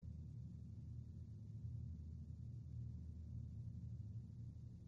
Vervolgens werd het geluid opgenomen aan de ontvangzijde voor de betonnen wand met voorzetwand en later ook zonder voorzetwand.
ontvang-met.jpg (772 bytes) signaal ontvangzijde met voorzetwand
Zoals we kunnen opmerken, is de geluidisolatie van een constructie met voorzetwand erg goed, het geluiddrukniveau neemt af met zo'n 56 dB.